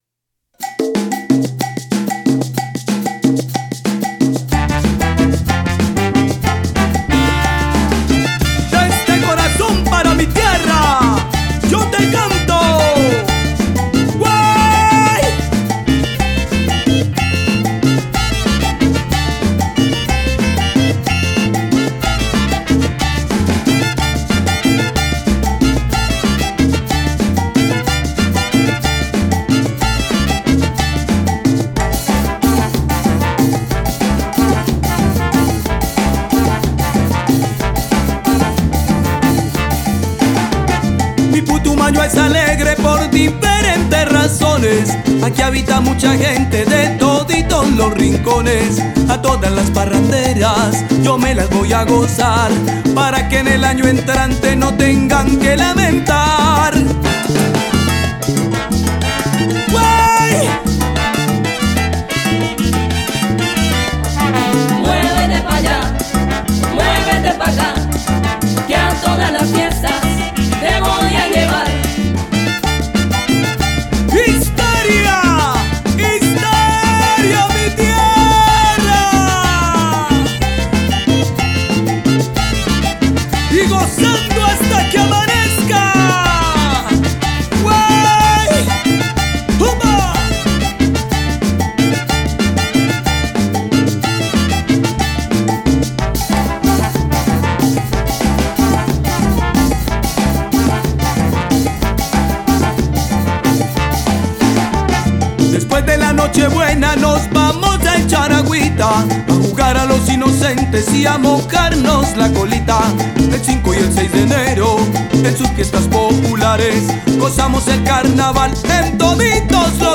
nueva versión fiestera